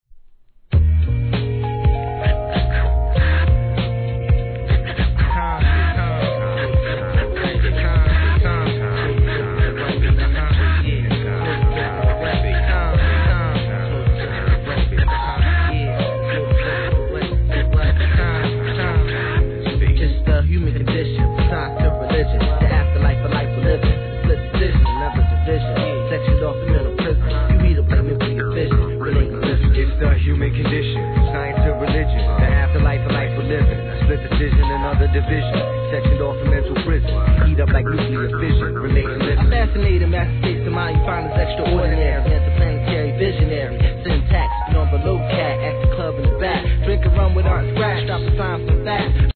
HIP HOP/R&B
*チリチリはSAMPLING音源の物です